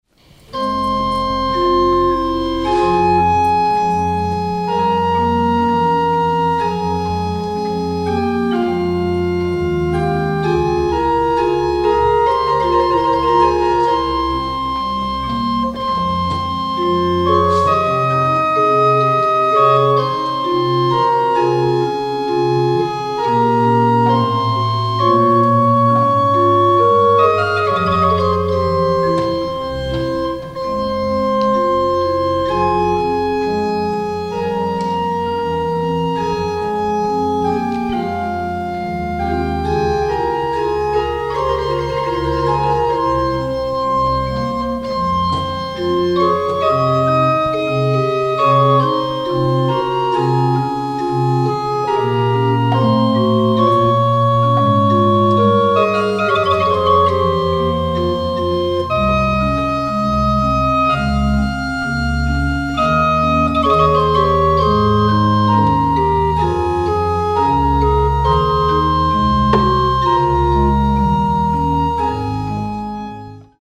Excerpt from "Ich Ruf zu Dir, Herr Jesu Christ" (BWV 639), a choral prelude by Johann Sebastian Bach (1685-1750).
in the Calvin Auditorium, Geneva.